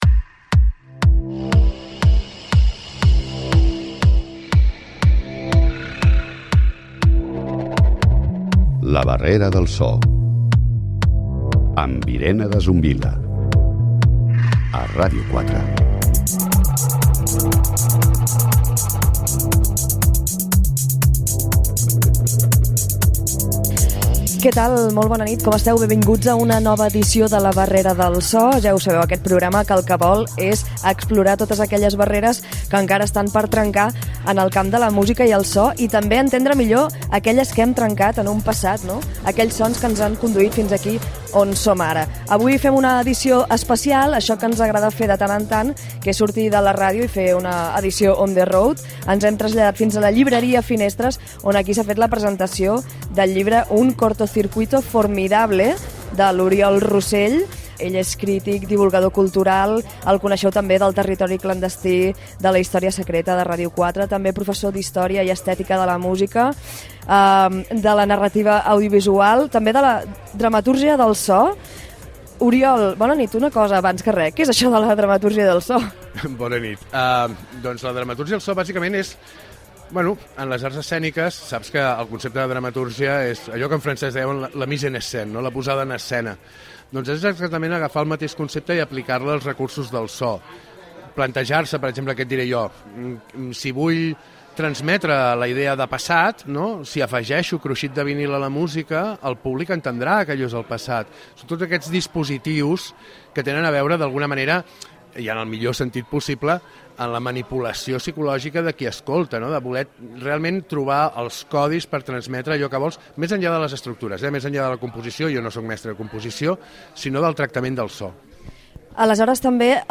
Cultura
Presentador/a